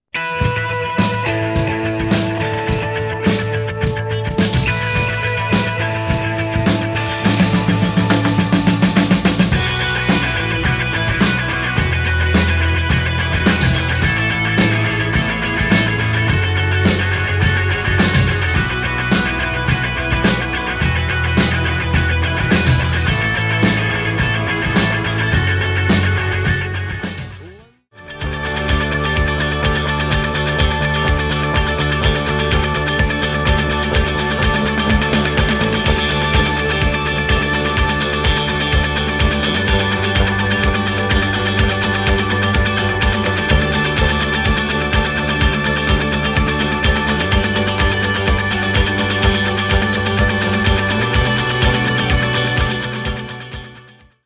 This morning I was awakened by some men working in the street using a jackhammer.
jackhammer.mp3